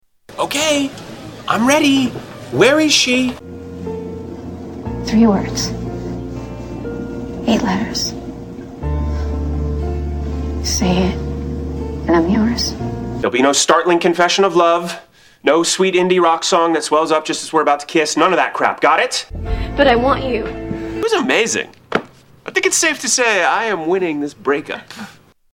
Tags: Soundboards Talk to each other Mash up Mash up clips Mash up sounds Soundboard talking